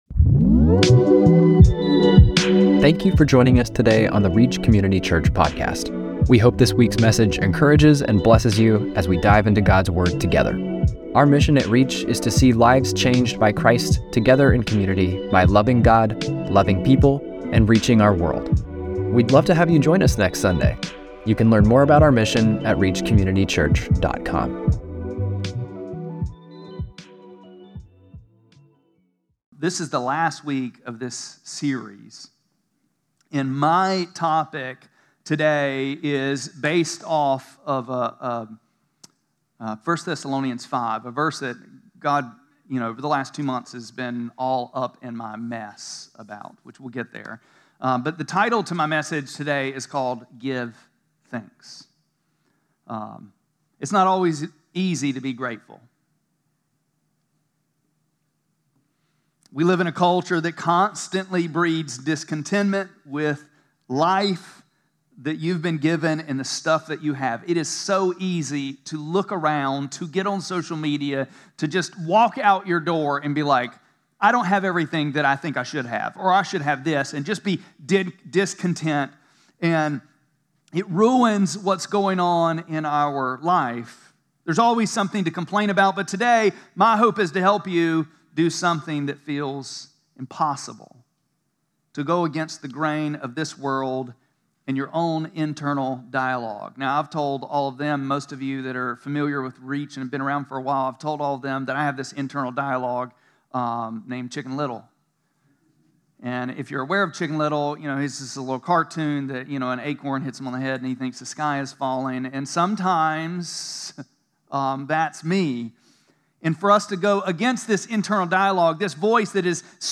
7-27-25-Sermon.mp3